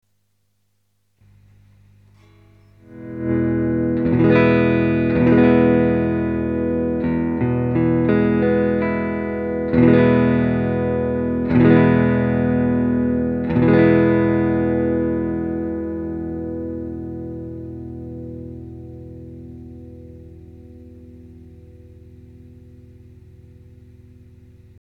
個人的にええと思うだば。
ピックのアタック感もえーだば。
■アンプ：Fender　Pro-Junior（15W)
■マイク：Seide　PC-VT3000/SHURE　SM57-LCE